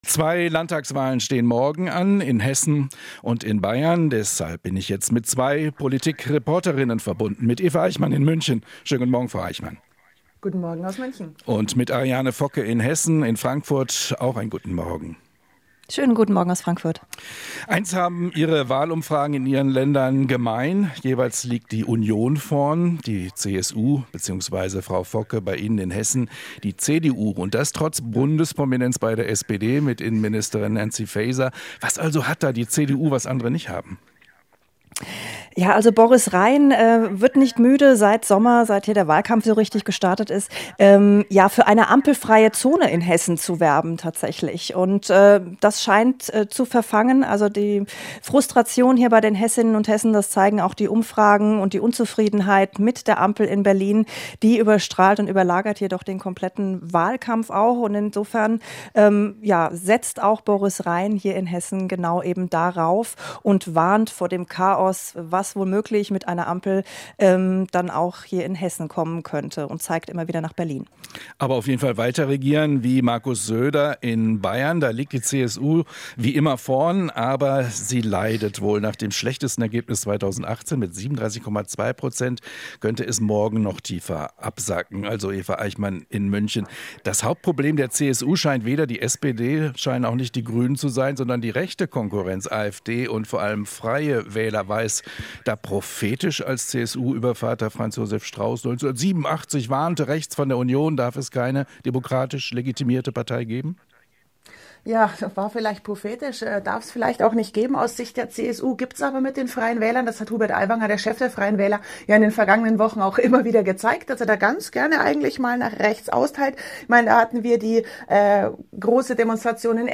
Interview - Vor den Landtagswahlen in Bayern und Hessen